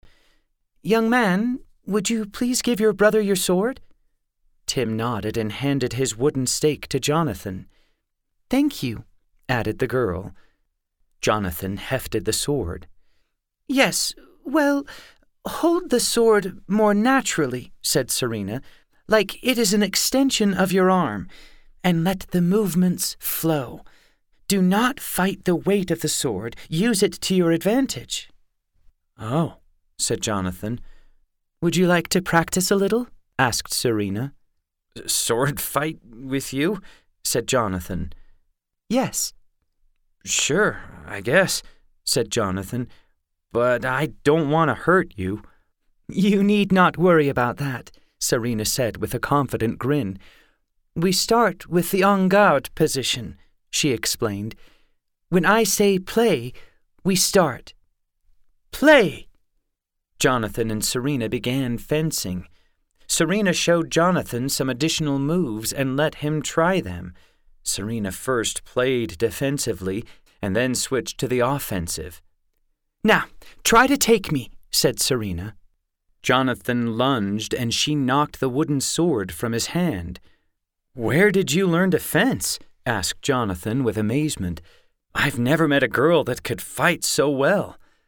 Fun Audiobooks!